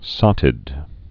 (sŏtĭd)